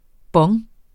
Udtale [ ˈbʌŋ ]